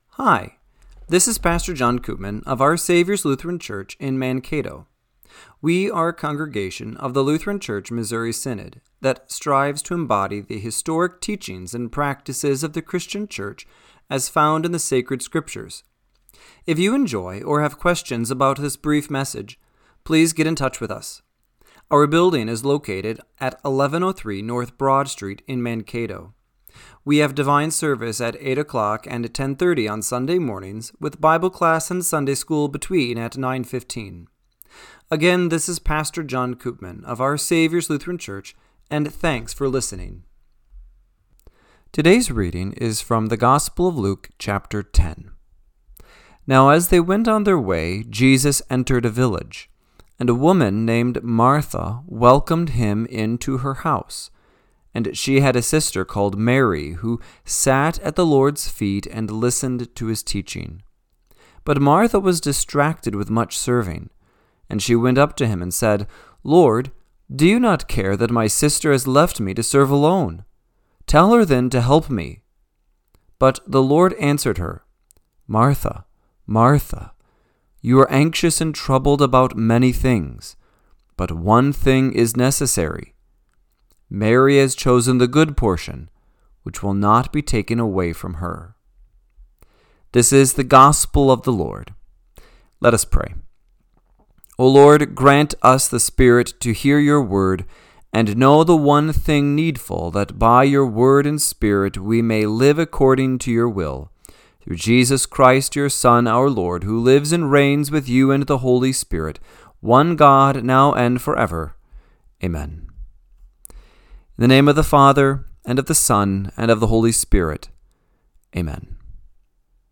Radio-Matins-7-20-25.mp3